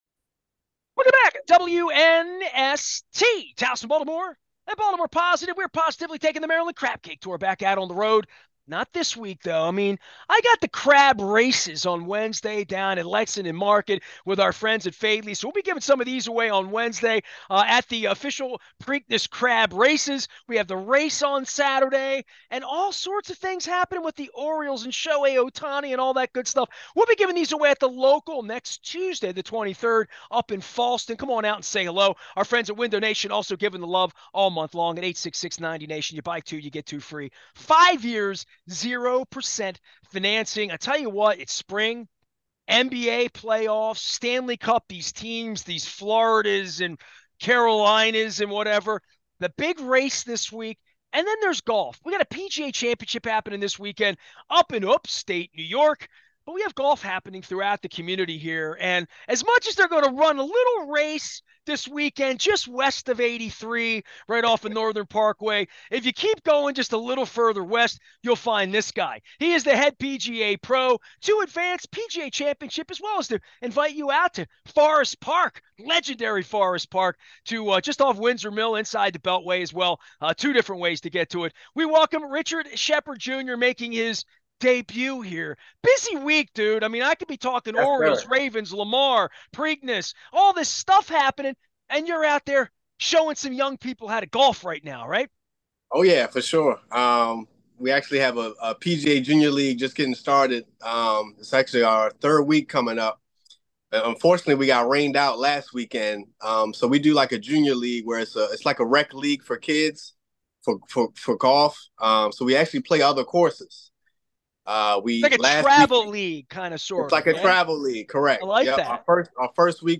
from the greens at Forest Park to discuss getting kids into the game and previews PGA Championship